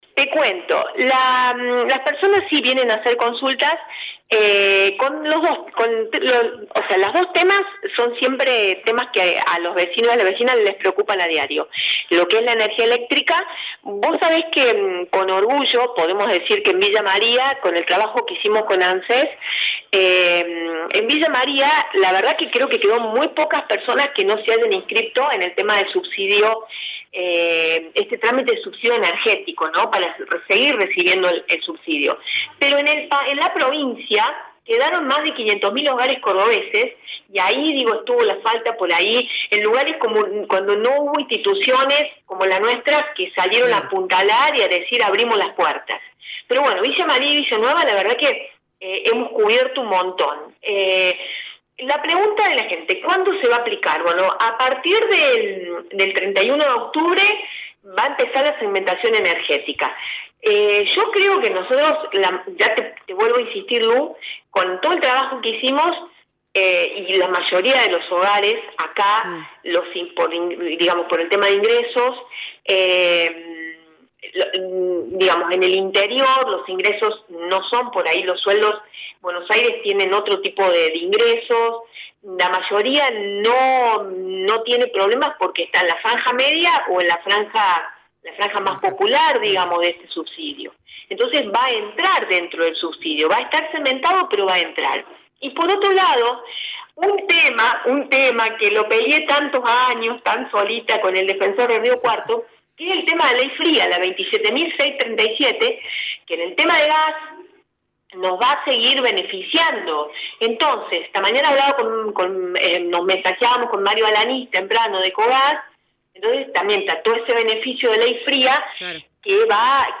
La Defensora del Pueblo, Alicia Peresutti, brindó detalles en torno a cómo avanza la segmentación y los subsidios a la luz y el gas.